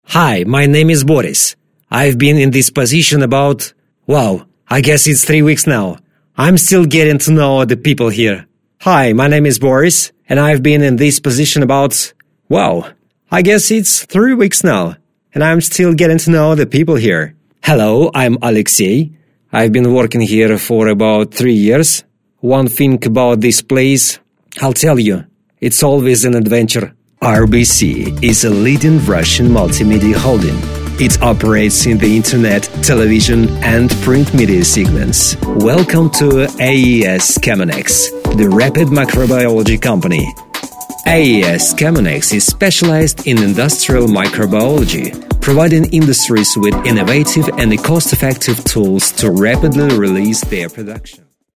Masculino
English (Russian accent)
Rich, warm, cool, young, adult